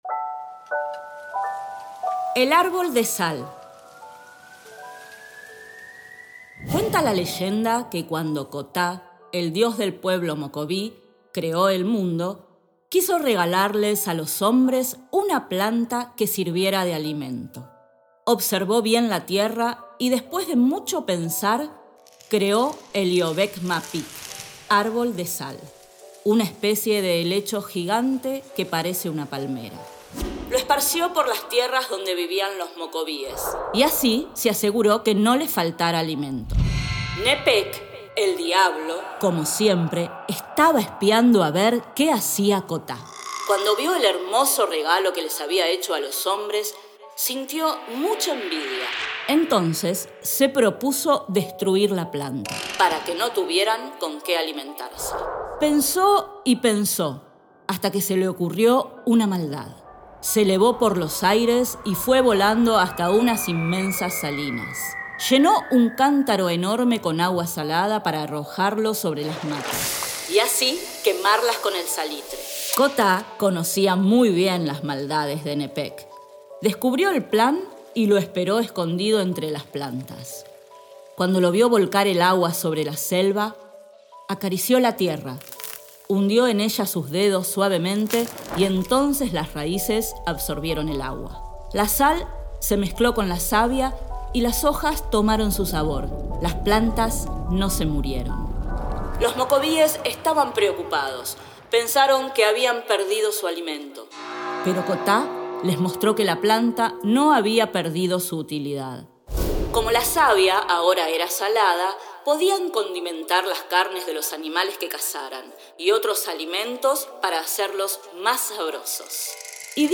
Cuentos contados: Leyenda del árbol de sal